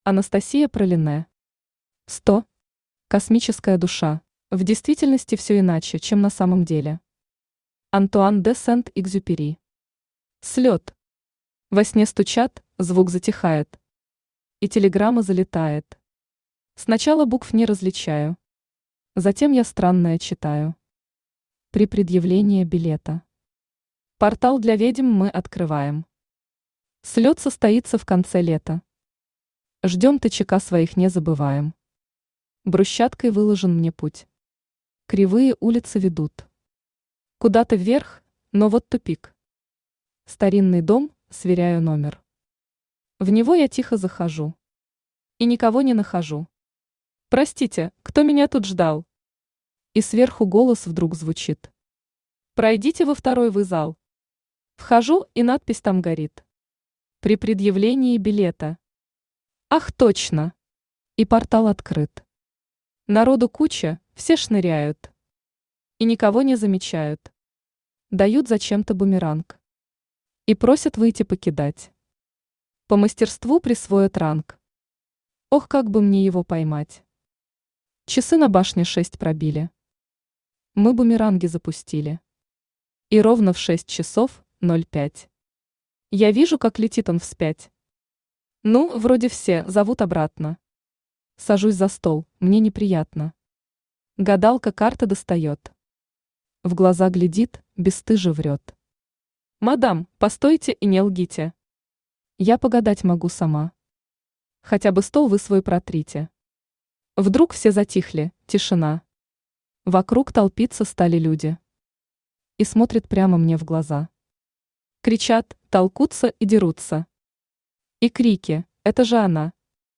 Аудиокнига Сто. Космическая душа | Библиотека аудиокниг
Космическая душа Автор Анастасия Пралине Читает аудиокнигу Авточтец ЛитРес.